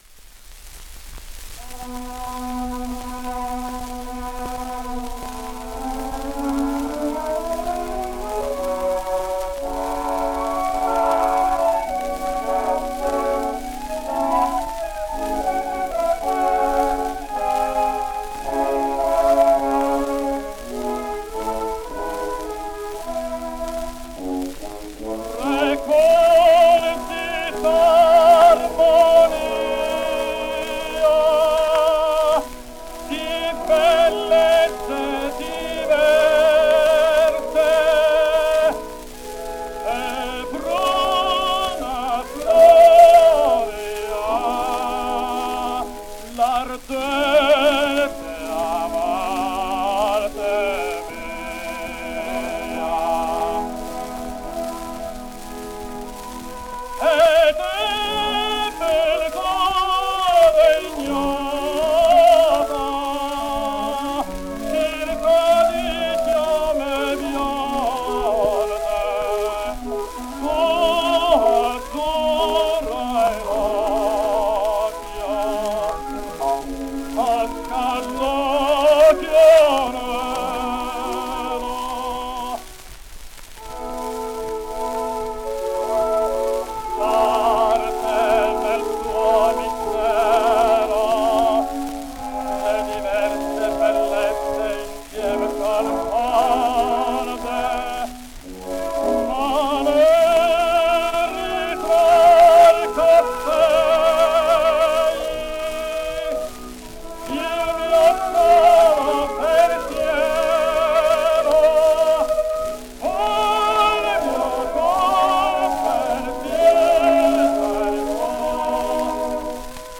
His natural voice is not that bad, and his musicality is absolutely sufficient; the voice production is somewhat amateurish, however.
Kalliope, London, 1912